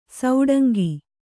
♪ sauḍangi